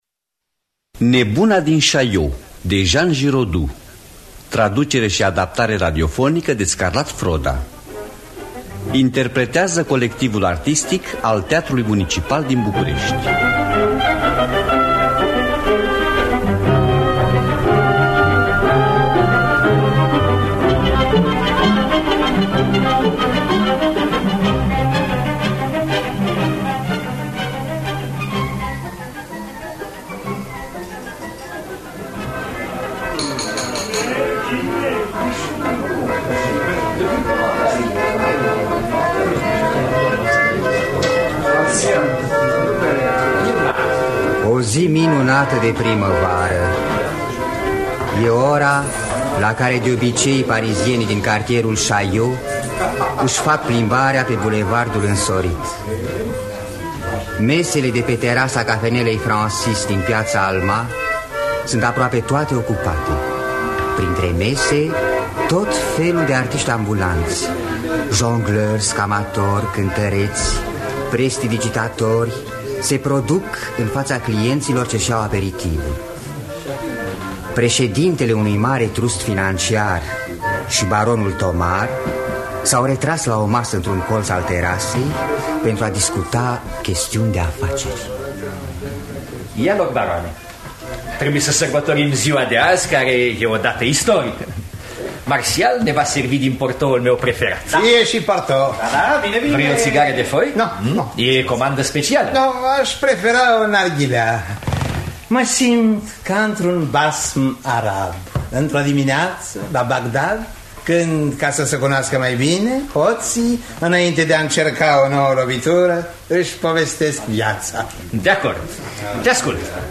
Traducerea și adaptarea radiofonică de Scarlat Froda.